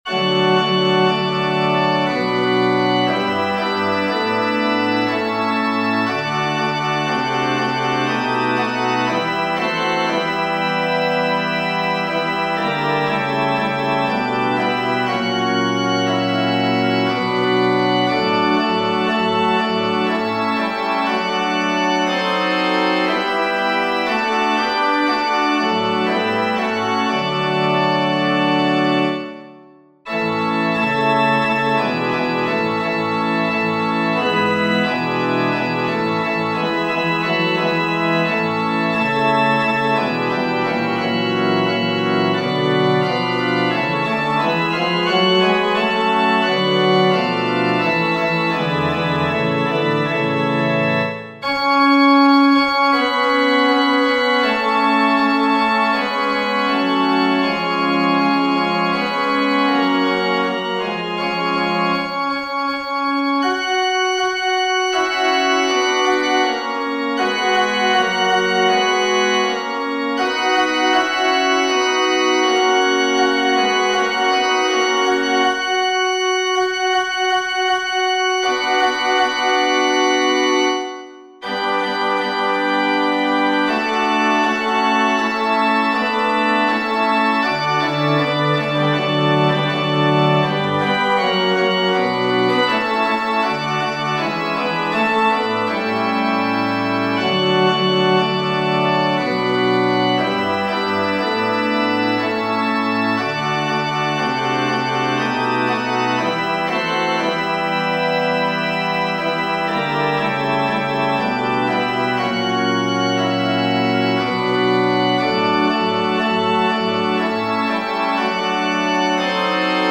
FF:HV_15b Collegium male choir